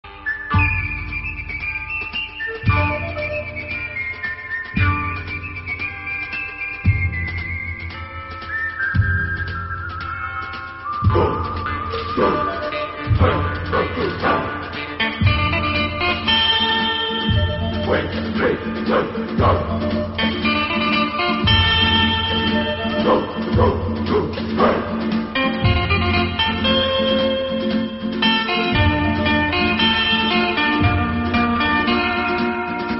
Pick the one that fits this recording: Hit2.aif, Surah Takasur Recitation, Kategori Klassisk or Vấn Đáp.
Kategori Klassisk